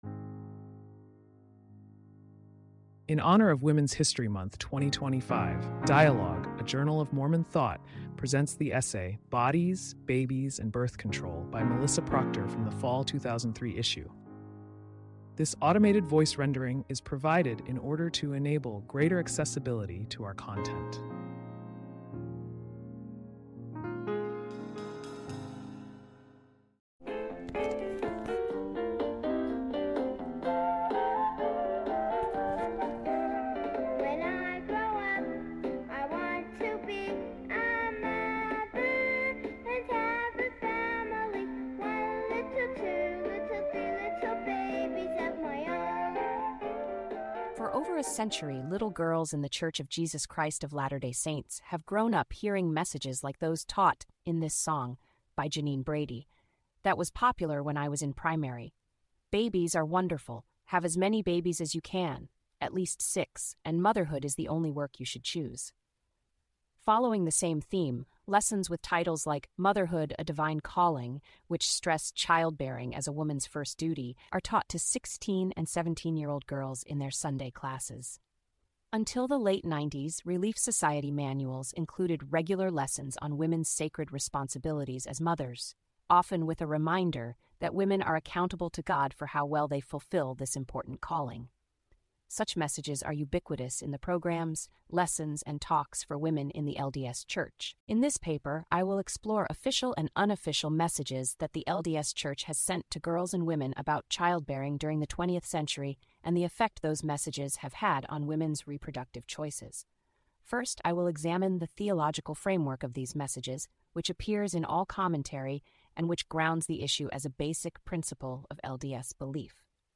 This automated voice rendering is provided…